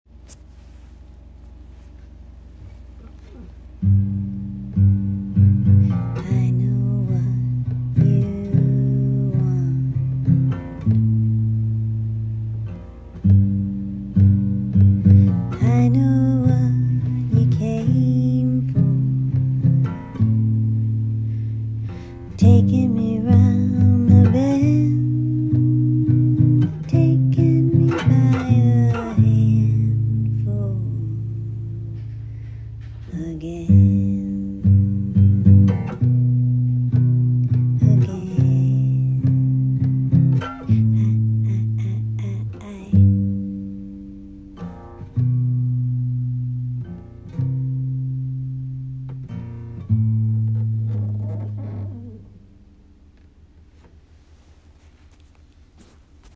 a home iphone recording on the living room floor, may 2024. i know what you want. i know what you came for. taking me round the bend. taking me by the handful. again.